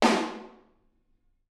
R_B Snare 03 - Room.wav